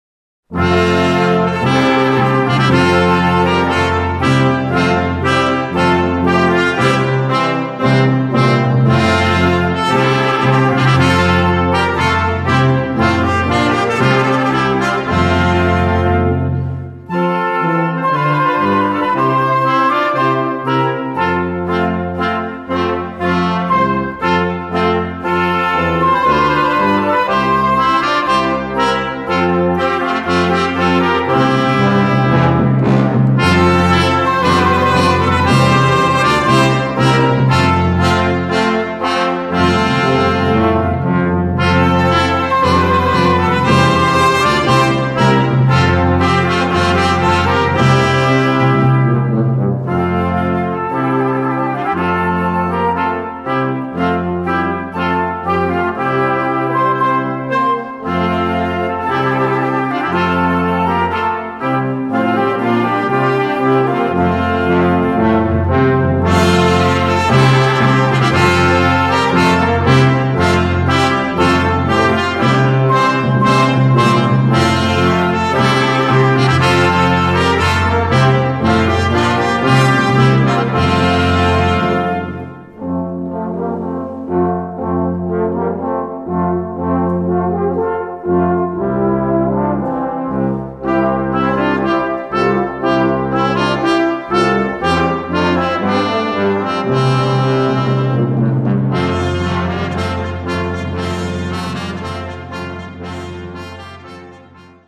Gattung: 5-Part-Ensemble
Besetzung: Ensemblemusik für 5 Blechbläser
Percussion optional.